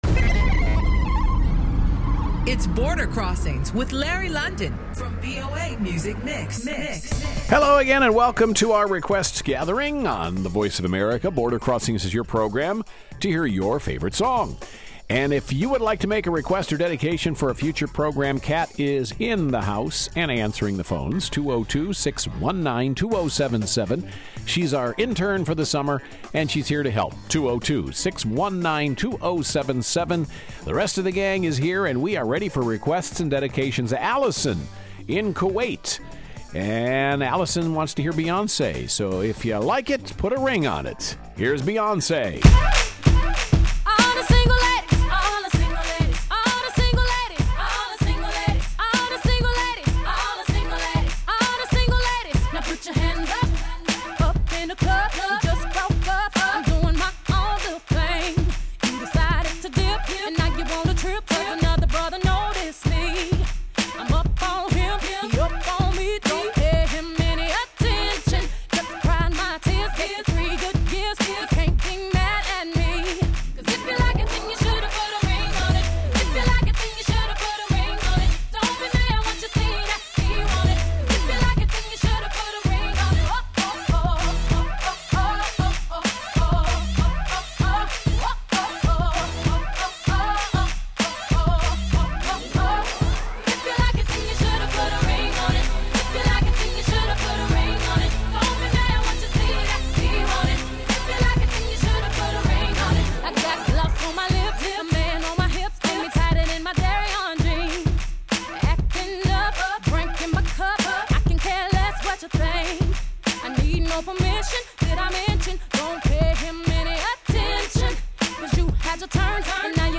VOA’s live worldwide international music request show